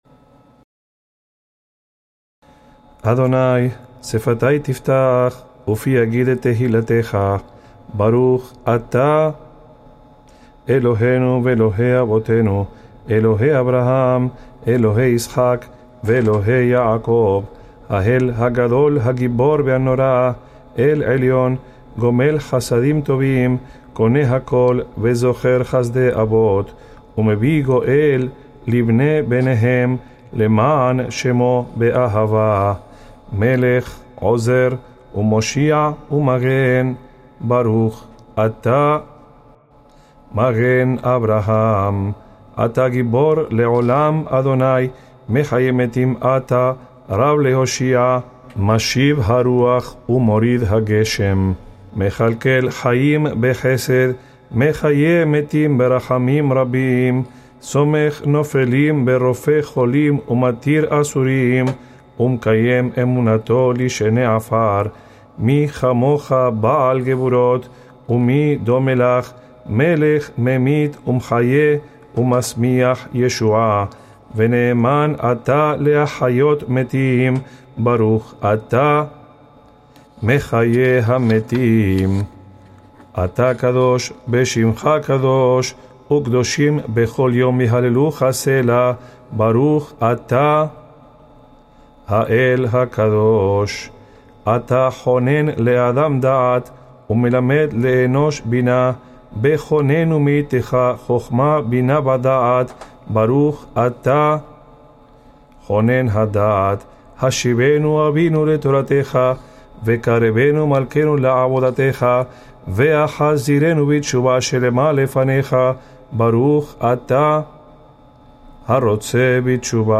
5. AMIDA (Version lenta de la Amida. Se debe agregar el nombre de HaShem luego de cada : Baruj Atá)